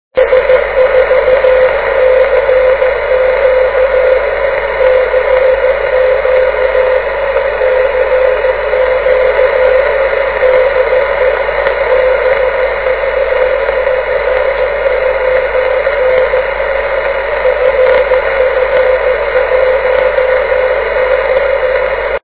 ff1x_beacon.wav